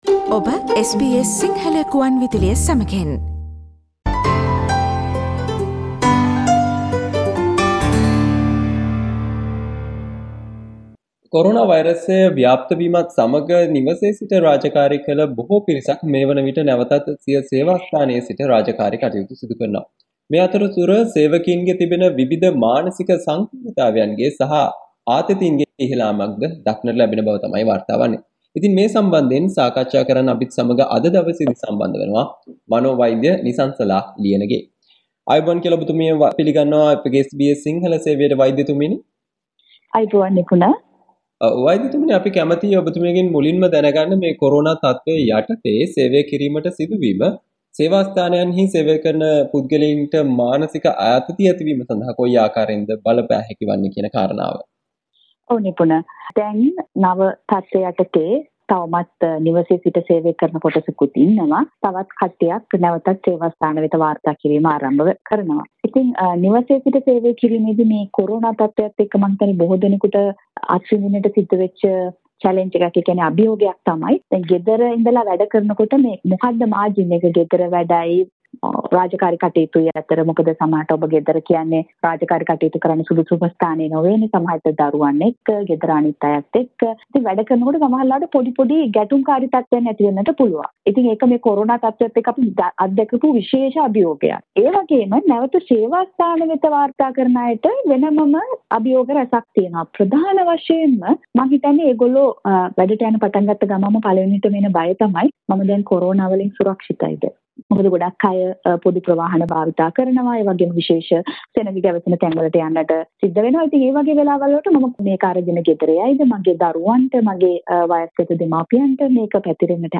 SBS Sinhala Discussion